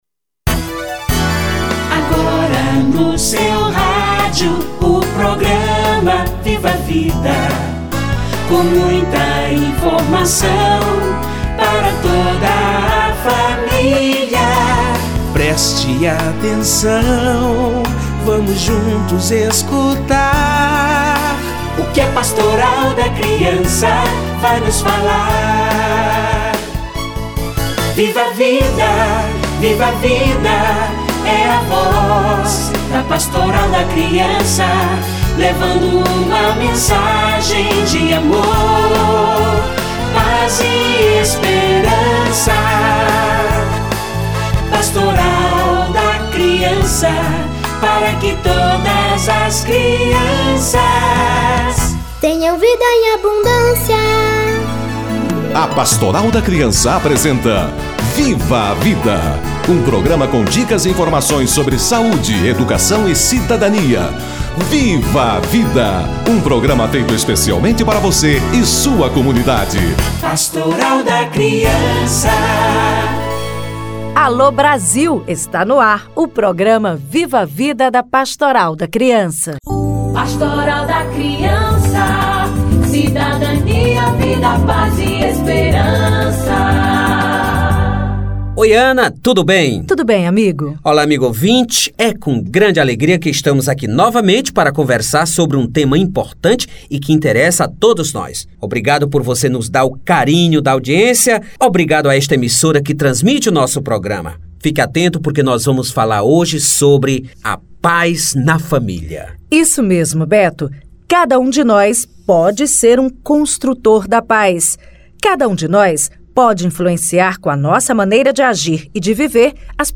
A paz começa em casa - Entrevista